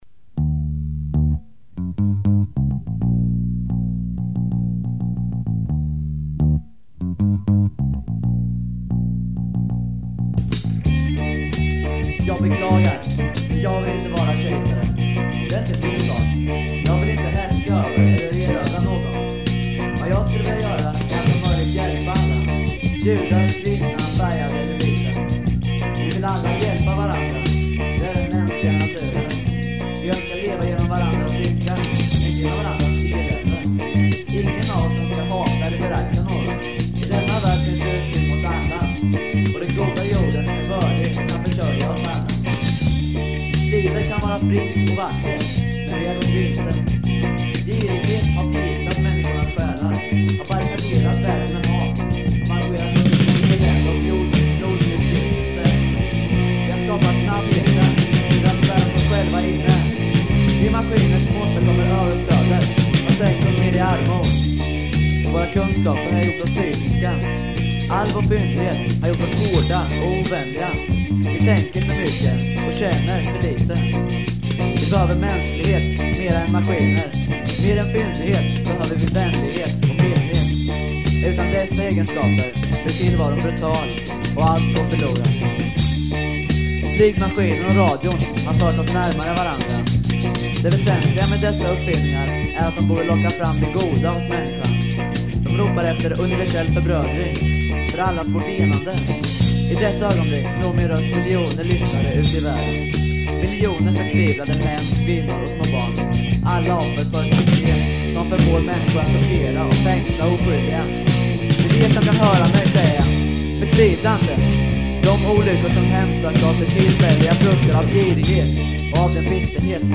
Bass
Drums, Keyboards
Guitar